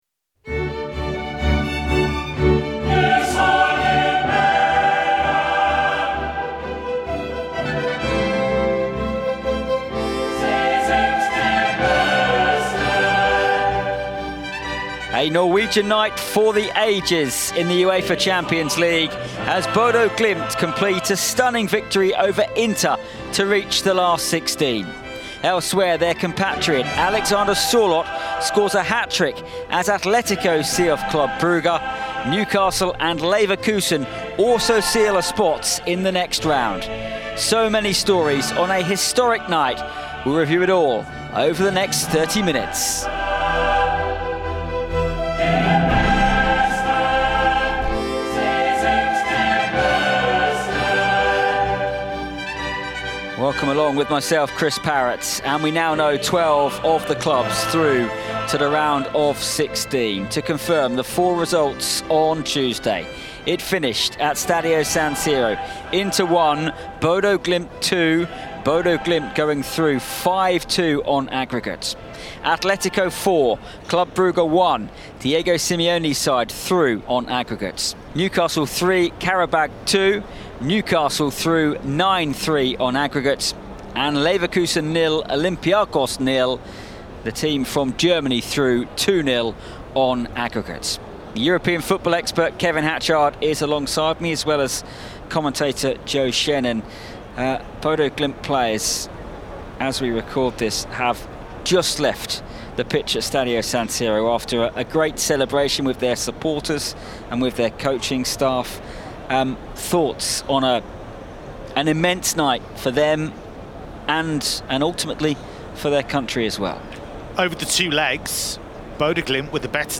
We hear from Gala striker Victor Osimhen and Juve defender Lloyd Kelly.
We hear from the scorer of their first goal and UEFA Player of the Match, Jens Petter Hauge.